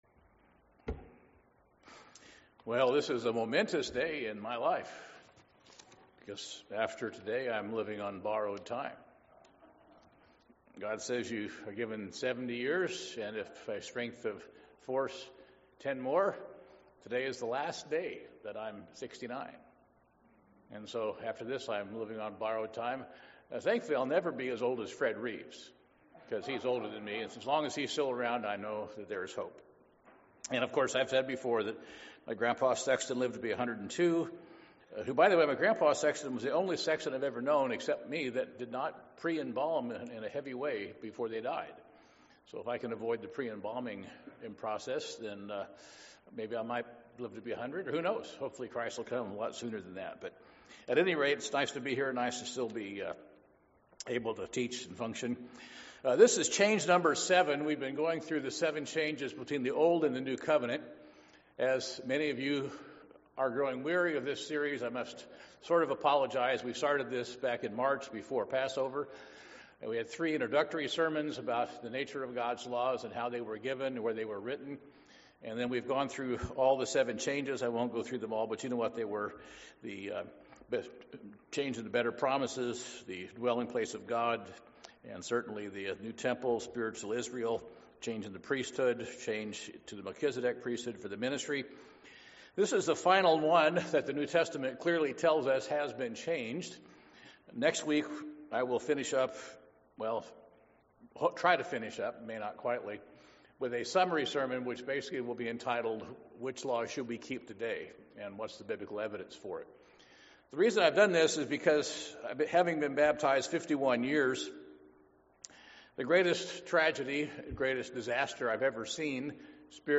Sermons
Given in Portland, OR